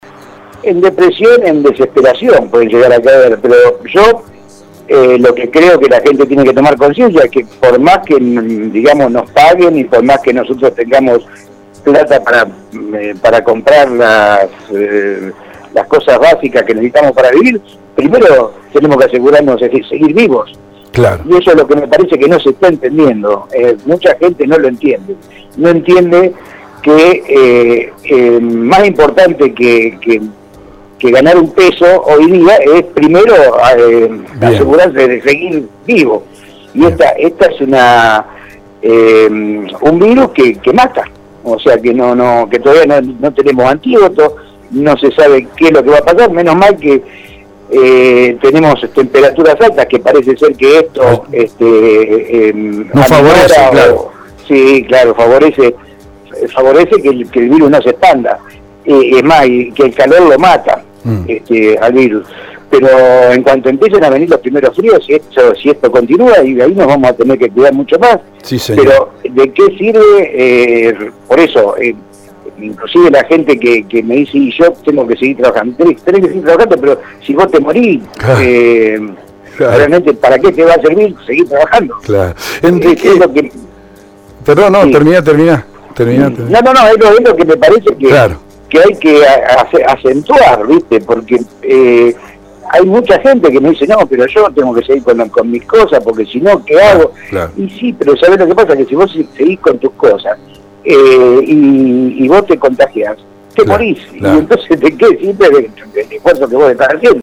Interesante entrevista de este martes